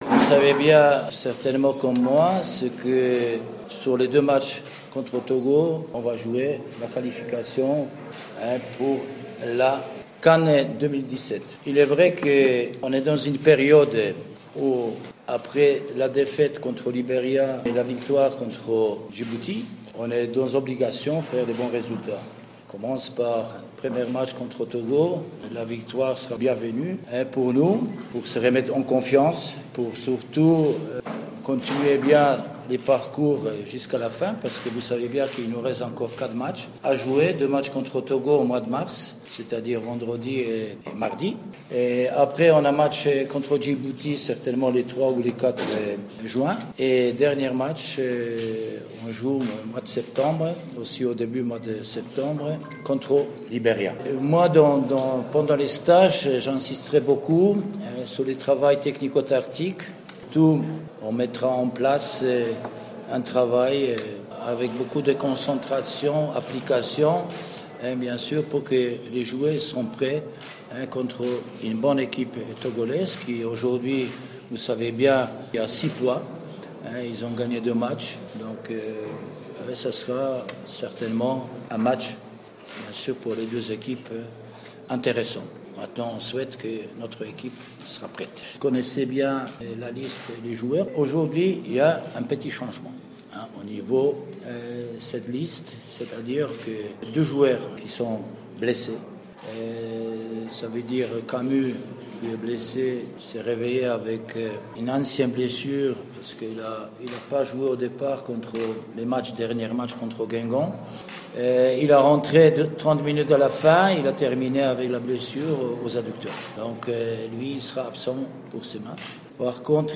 مواجهة الطوغو : ندوة صحفية للمدرّب الوطني كاسبرجاك
عقد اليوم المدرّب الوطني هنري كاسبرجاك ندوة صحفية في قاعة الندوات بالملعب الأولمبي بسوسة قبل إنطلاق الحصّة التدريبيّة الأولى للمنتخب الوطني على معشّب أولمبي سوسة.